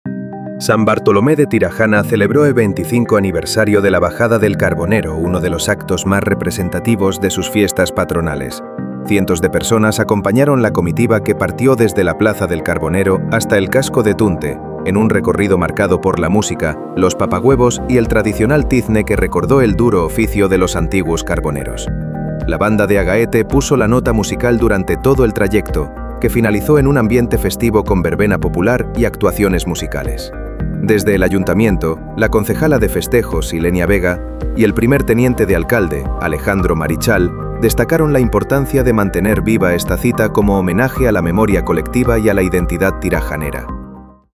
NOTICIA-FIESTAS-SAN-BARTOLOME.mp3